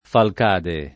[ falk # de ]